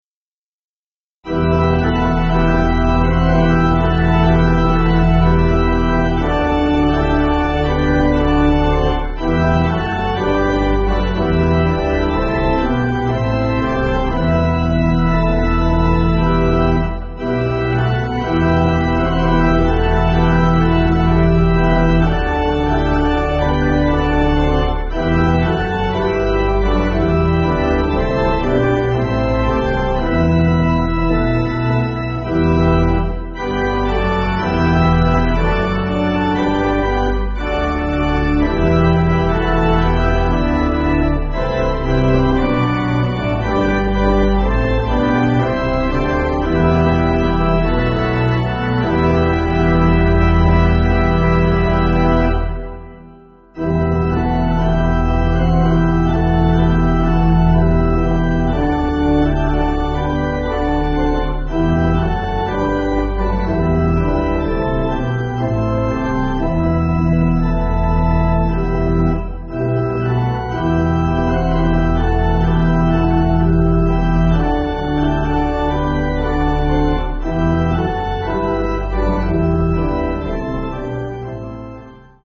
Organ
(CM)   3/Em